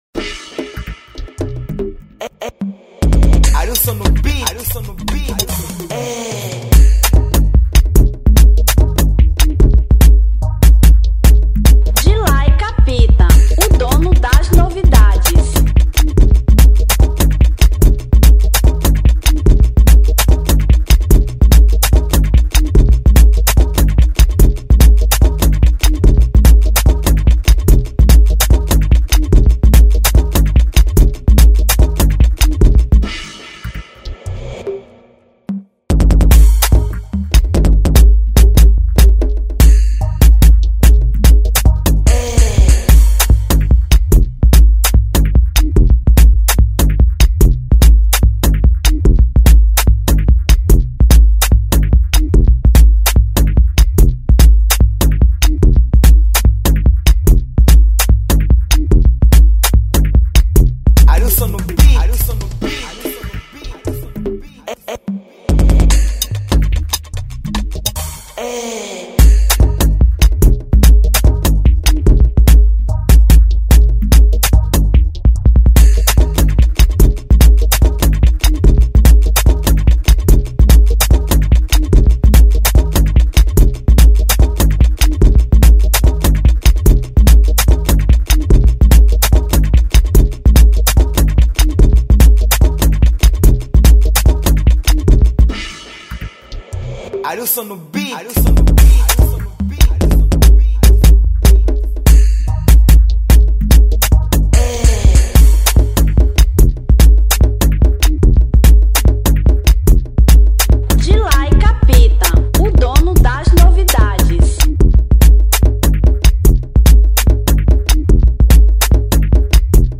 Instrumental 2025